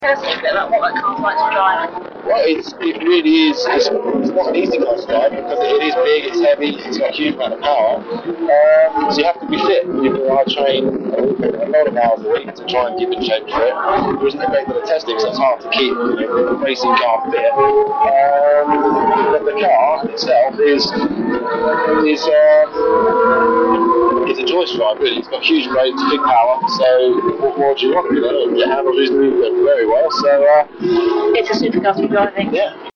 This interview was conducted in the Donington Park pitlane while the cars, complete with their V12 engines, were running out on the track. Therefore most of the audio from this interview is not of a usable quality but we’ve extracted some of the better bits and put them in the relevant places in the transcript.